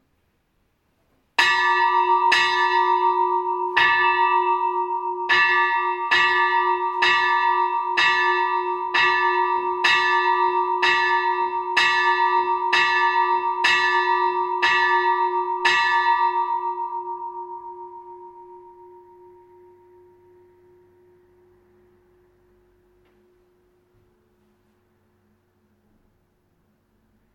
Die Glocke in Rieder zum Hören
Und: Die Glocke weist die markante, sehr alte Form der sogenannten Bienenkorb-Glocke auf.
kw00-53_rieder_bienenkorbglocke.mp3